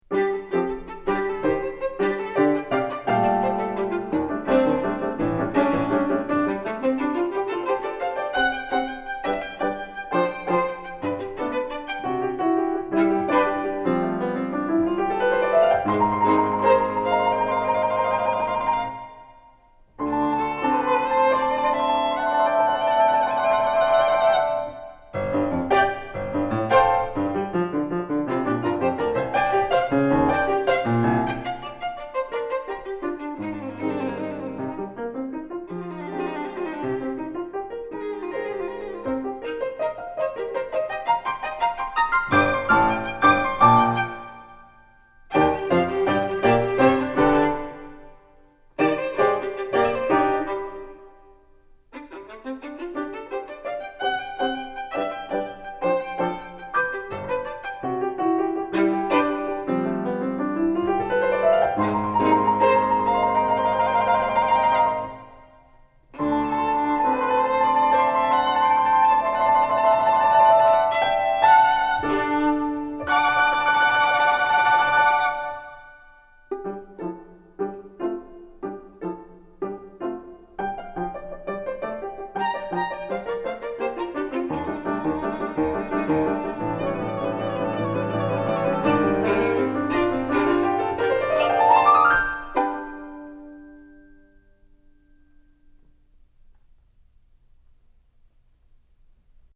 for Violin and Piano (1992)
in changing meters